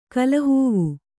♪ kallahūvu